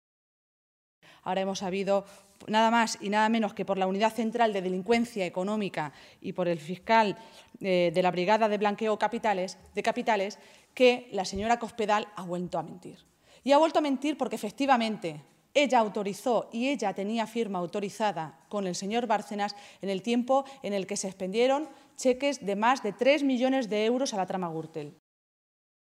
La portavoz socialista se pronunciaba de esta manera esta mañana en Toledo, en una comparecencia ante los medios de comunicación en la que recordaba que “Cospedal sigue sin explicar qué ha pasado con la presunta comisión ilegal de 200.000 euros para financiar su campaña electoral del año 2007”, denunciada por el propio Bárcenas ante el juez de la Audiencia Nacional, Pablo Ruz, el pasado 15 de julio.
Cortes de audio de la rueda de prensa